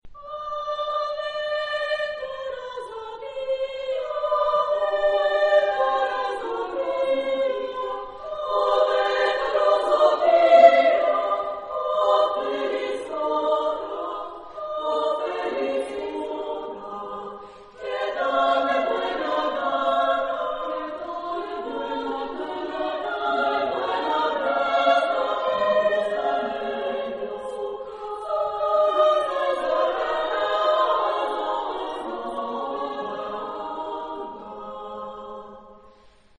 Genre-Style-Forme : Villanelle ; Profane
Type de choeur : SSA  (3 voix égales de femmes )
Tonalité : mode de sol
Réf. discographique : Internationaler Kammerchor Wettbewerb Marktoberdorf 2007